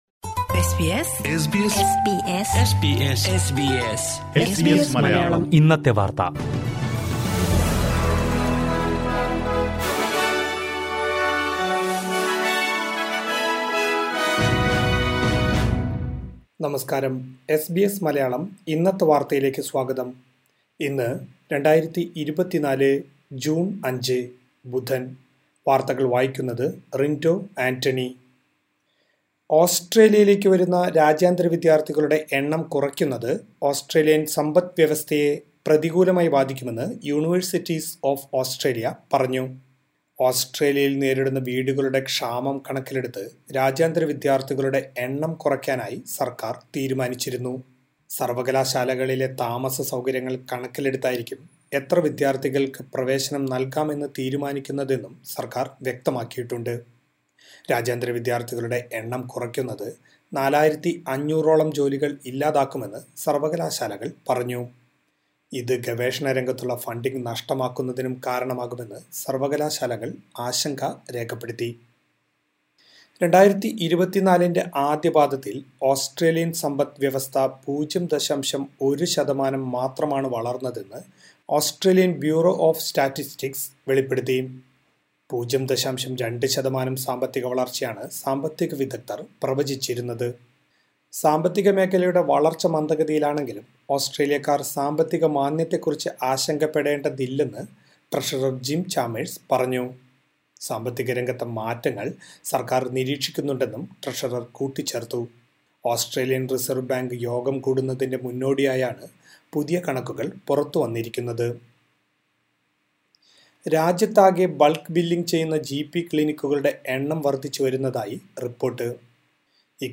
2024 ജൂൺ അഞ്ചിലെ ഓസ്‌ട്രേലിയയിലെ ഏറ്റവും പ്രധാന വാര്‍ത്തകള്‍ കേള്‍ക്കാം...